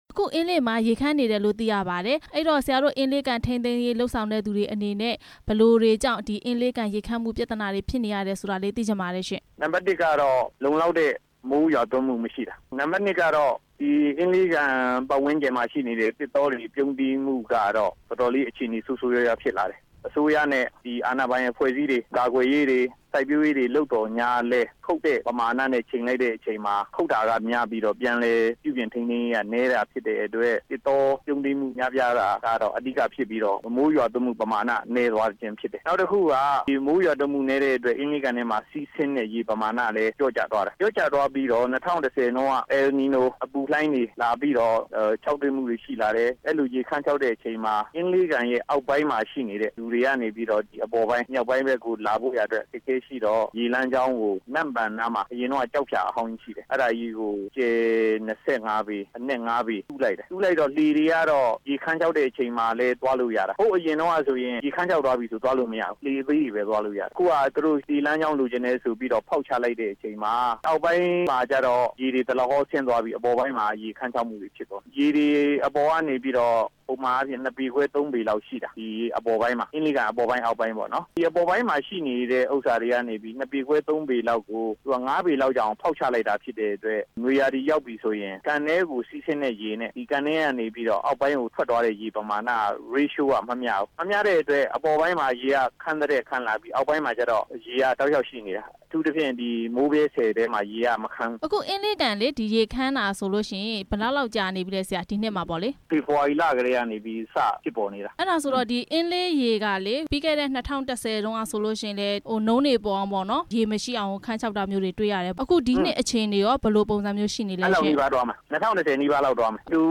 အင်းလေးကန် ရေခမ်းခြောက်တဲ့ အခြေအနေတွေနဲ့ ပတ်သက်ပြီး မေးမြန်းချက်